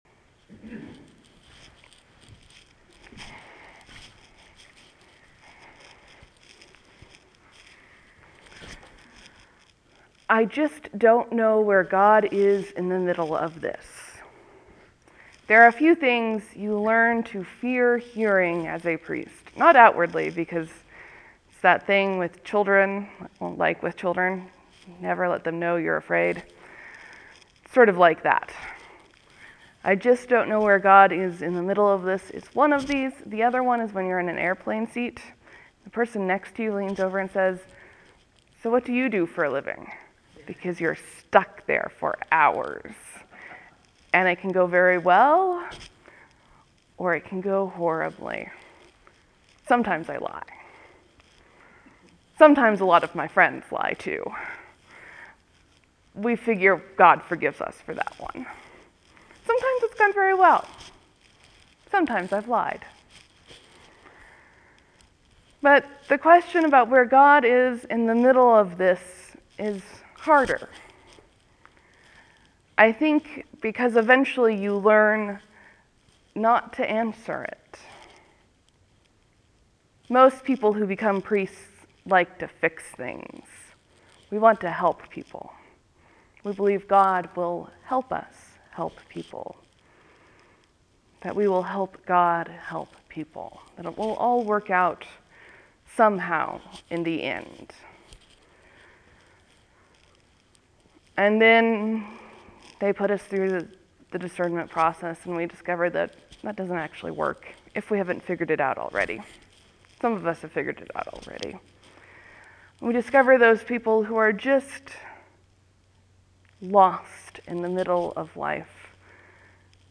(There will be a few moments of silence before the sermon starts. Thank you for your patience.)